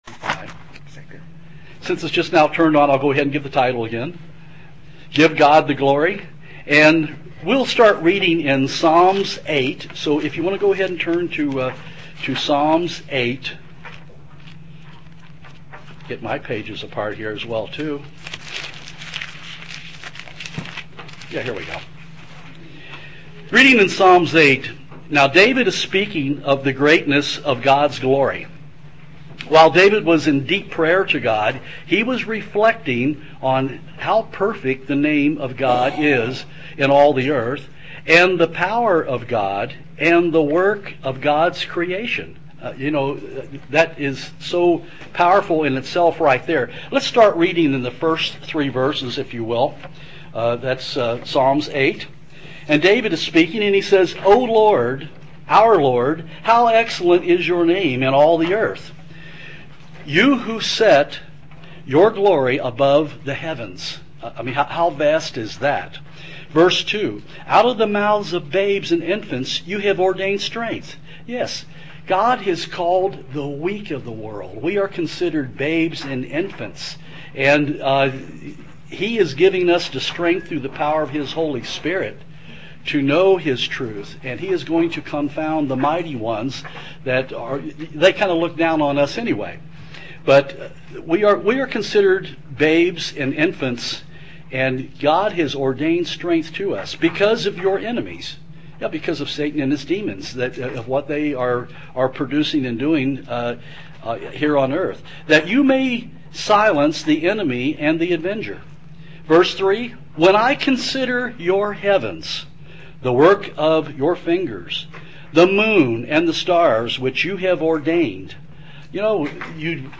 Given in Bowling Green, KY
UCG Sermon Studying the bible?